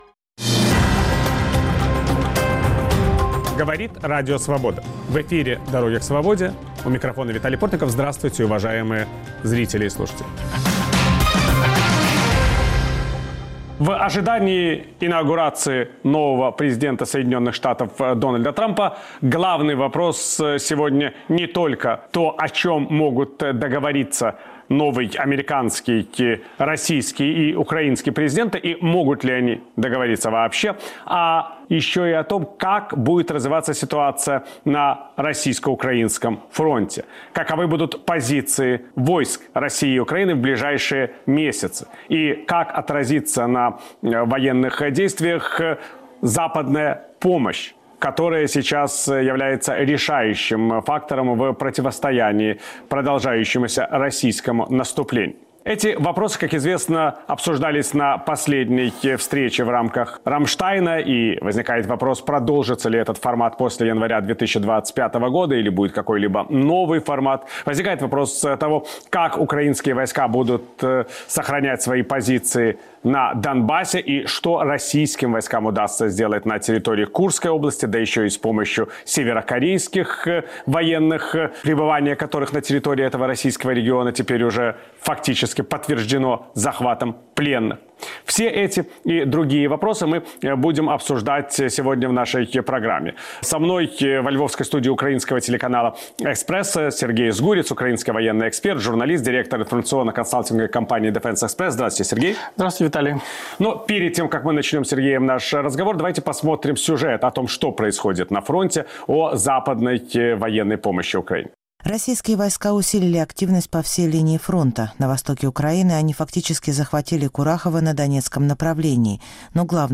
В студии - Виталий Портников и его гости.